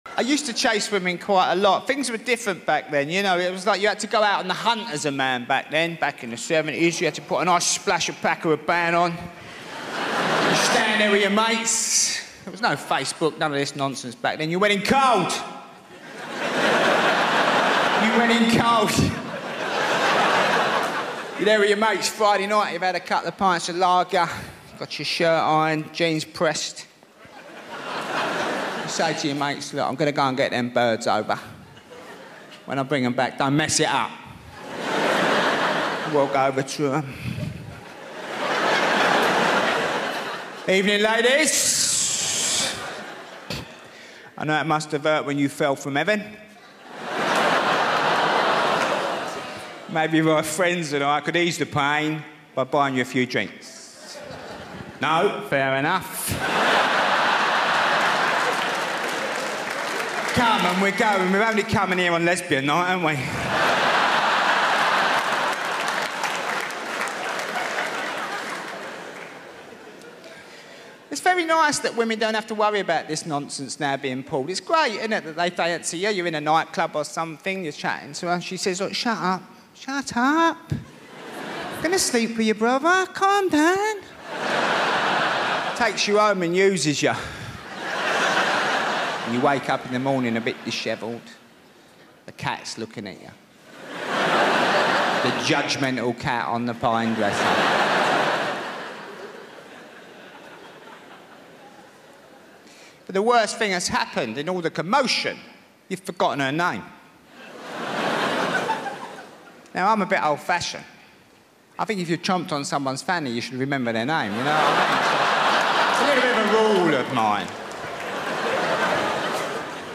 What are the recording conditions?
Before the first laugh even lands, you should know this: every episode of The Comedy Room places all advertisements right at the beginning, so once the show truly starts, nothing interrupts the flow, the rhythm, or the feeling.